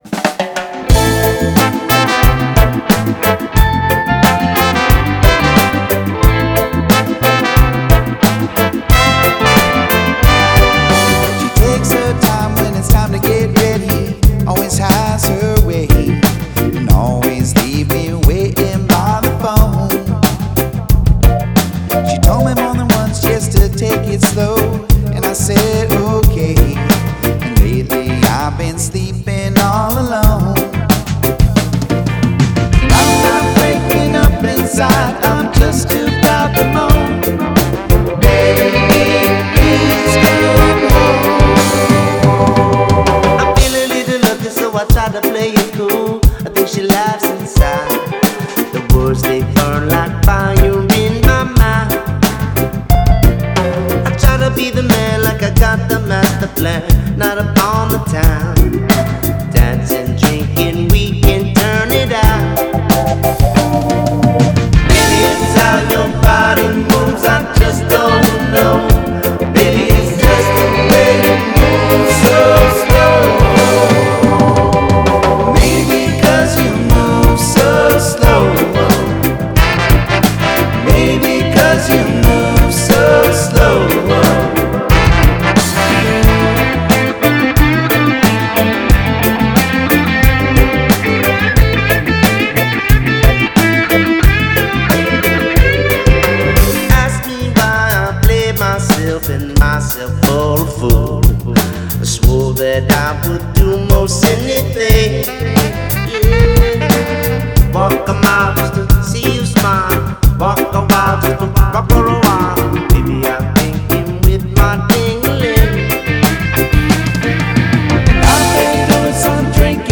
reworked and remastered versions
Reggae Rock News Tags